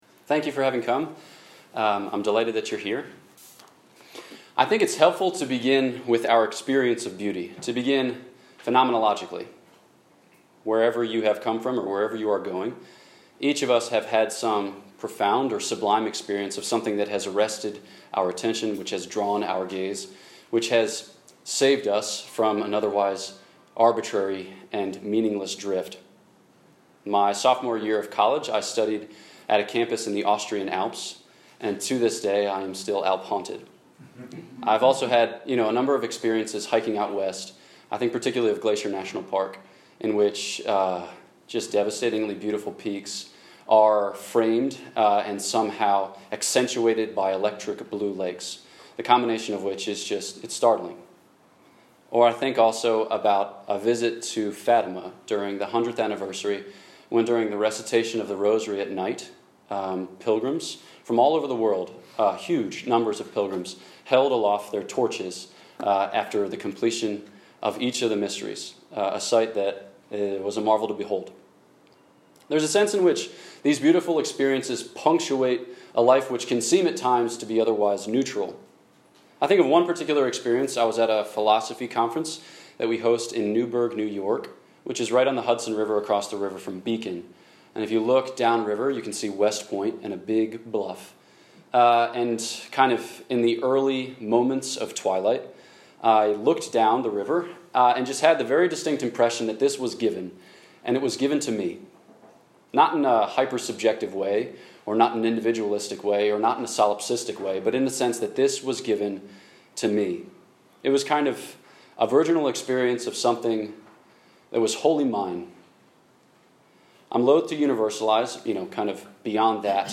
Summary This talk was given September 25, 2019 at The University of Pittsburgh and Carnegie Mellon